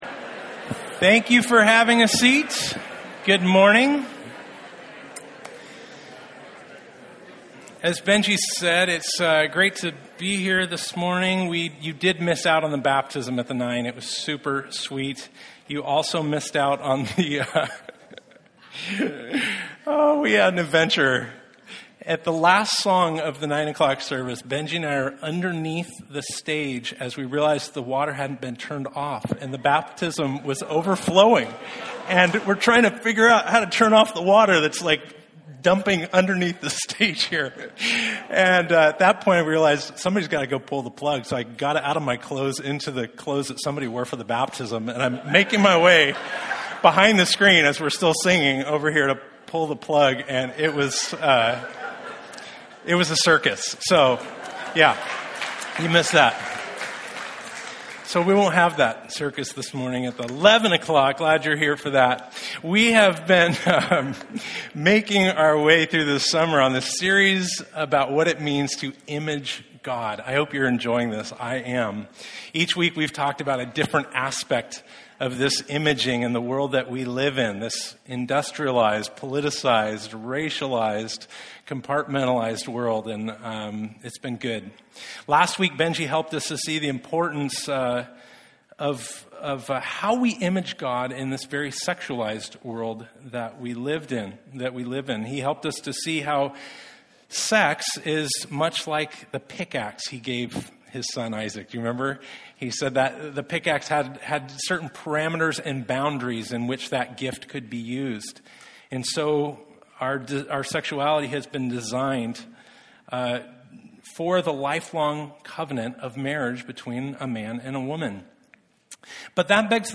Passage: 1 John 5:21 Service Type: Sunday